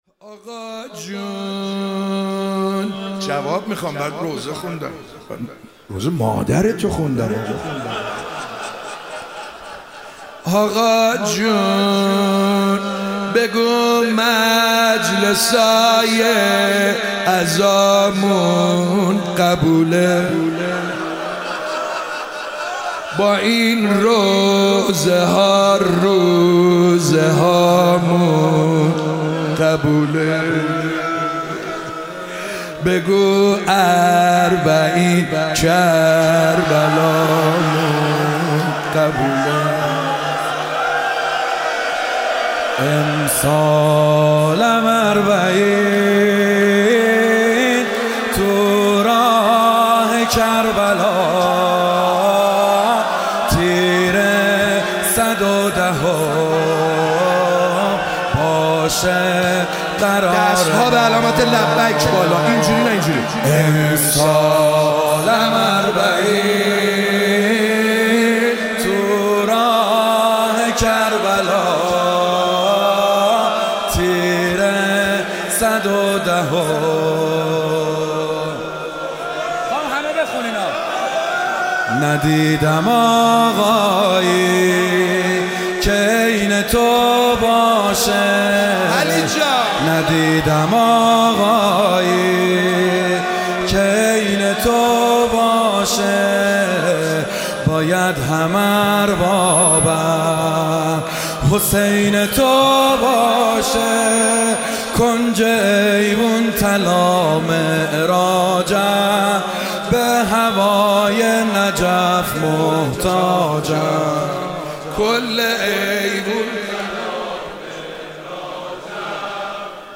شب 19 رمضان 97 - روضه - آقا جون بگو مجلسای عزامون قبوله